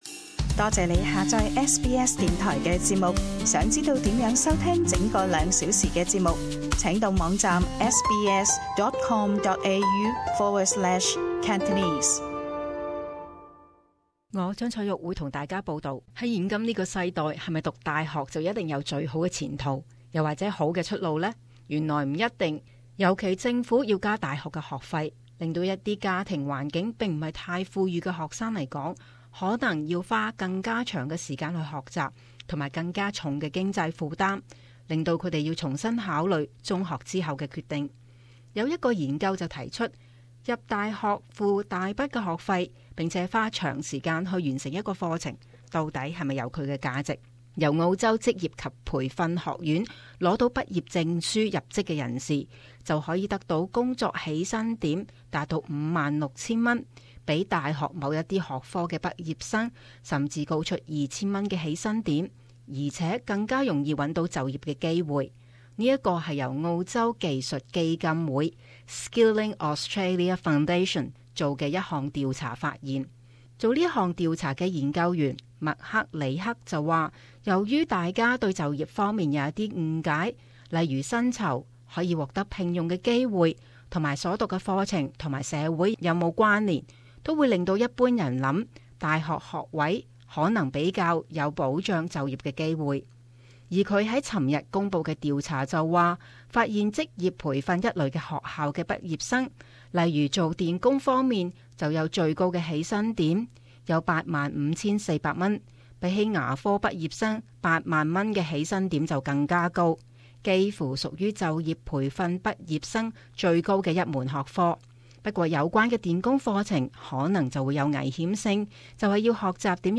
【時事報導】工科學校畢業生前途更好